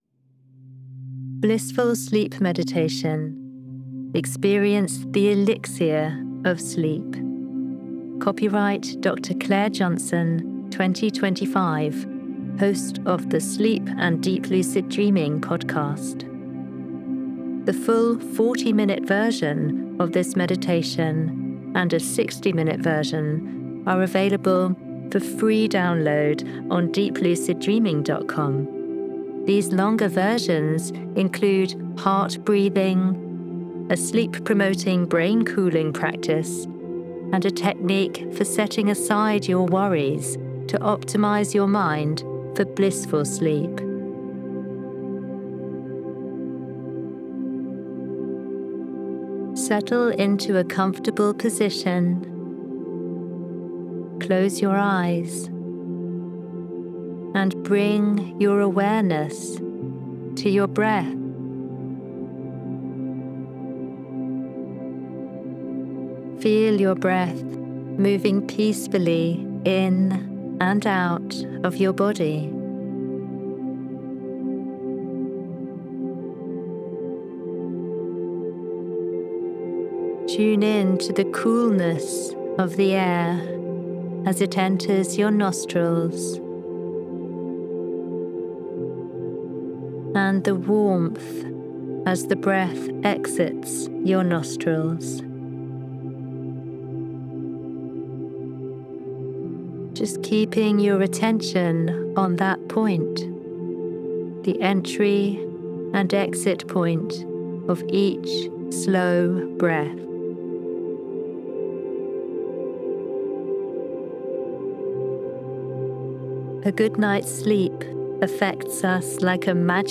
BONUS: Blissful Sleep Meditation 14 Minutes for Quick Lucid Refreshment